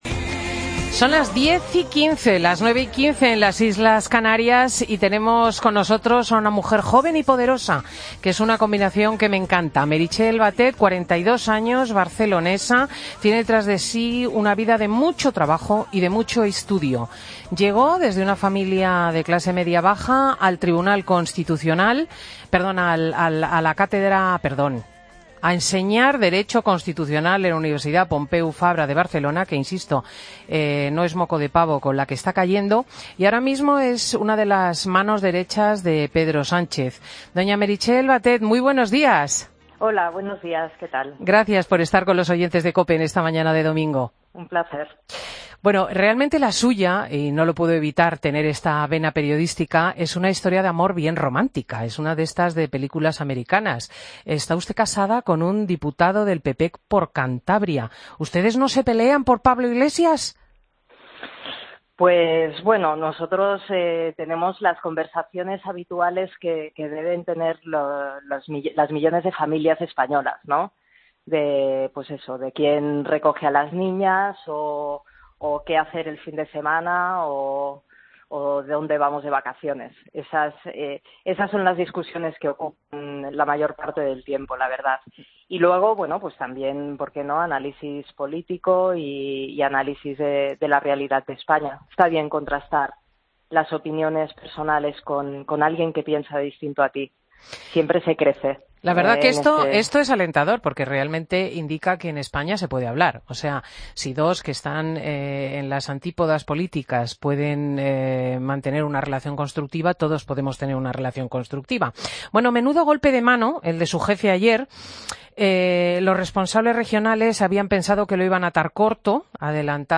AUDIO: Escucha la entrevista en Fin de Semana a Meritxell Batet, Diputada y Secretaria de Estudios y Programas del PSOE.